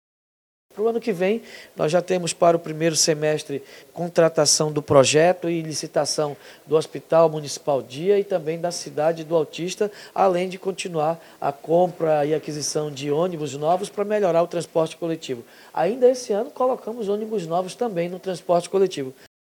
Um dia após a reeleição, o prefeito de Manaus, David Almeida, participou, nessa segunda-feira (28), de uma série de entrevistas a emissoras de rádio e TV, onde pontuou quais serão as prioridades para os próximos quatro anos de gestão.
02-SONORA-PREFEITO-DAVID-ALMEIDA.mp3